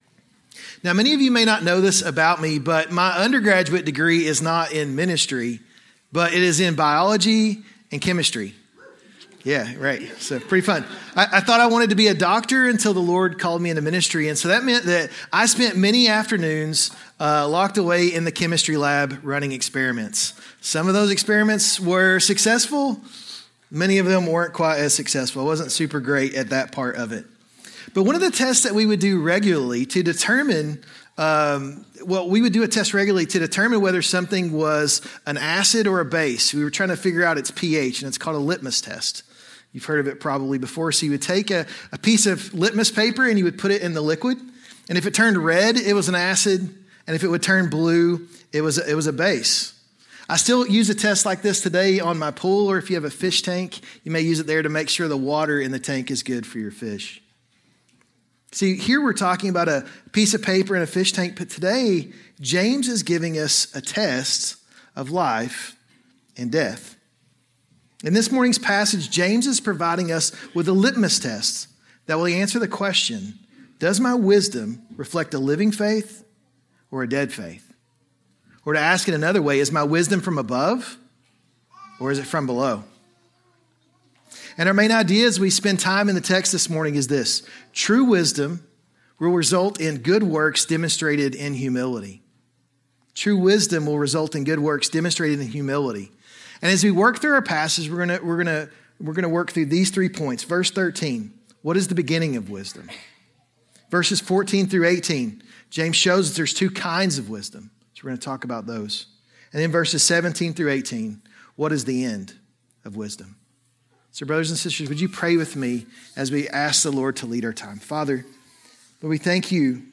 A sermon on James 3:13-18